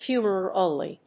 発音記号
• / fjúːməròʊl(米国英語)
• / fjúːmər`əʊl(英国英語)